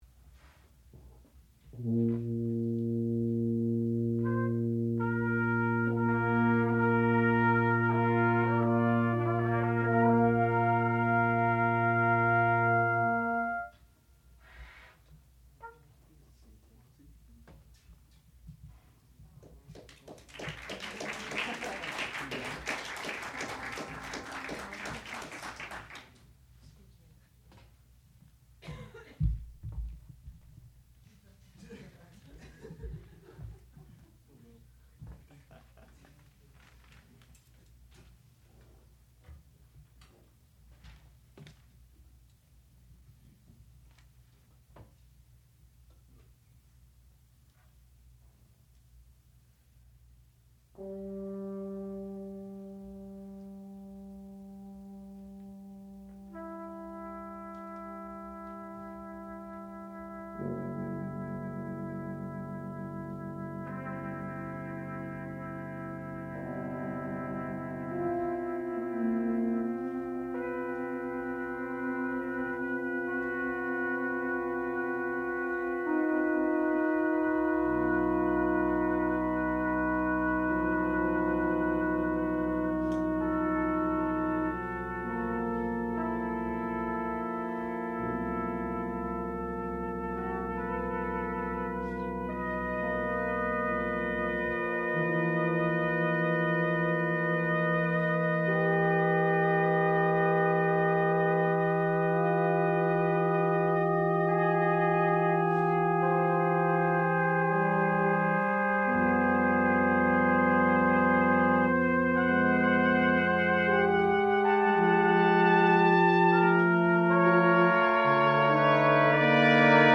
Brass Quintet
sound recording-musical
classical music
horn
trumpet
tuba
Advanced Recital